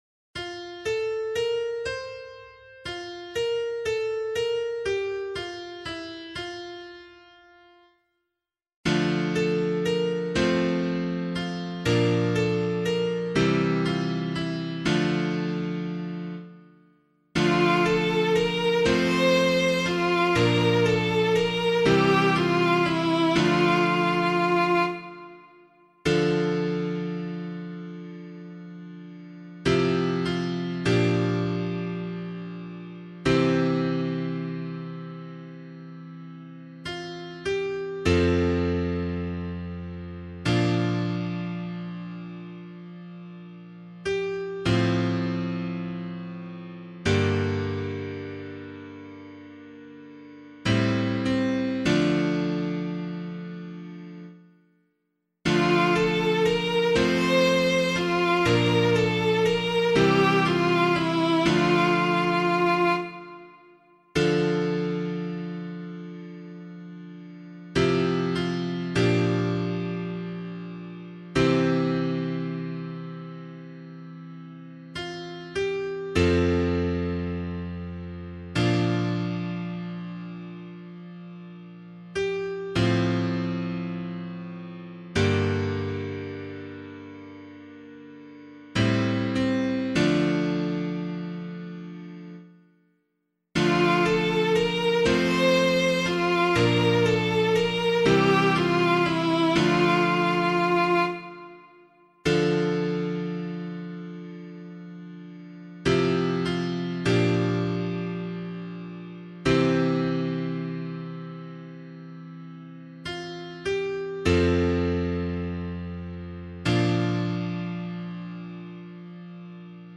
040 Ordinary Time 6 Psalm A [APC - LiturgyShare + Meinrad 1] - piano.mp3